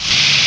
Mario 64 sound effects